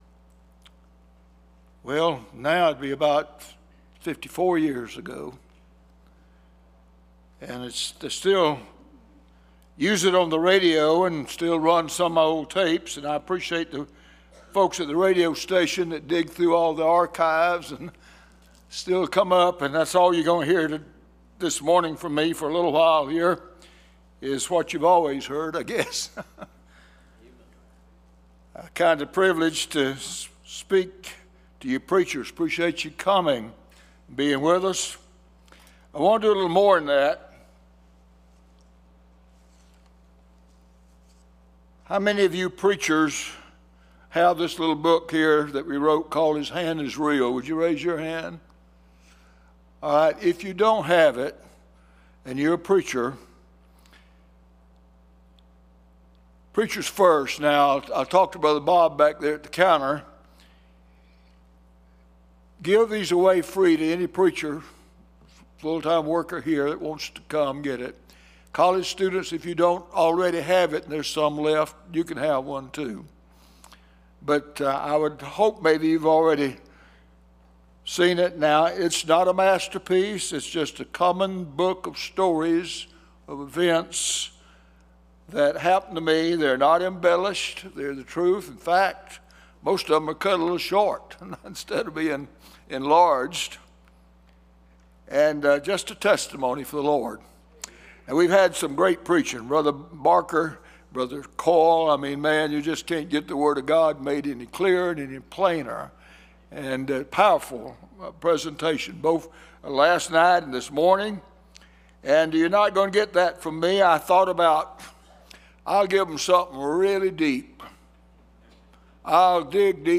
Series: 2025 Bible Conference
Preacher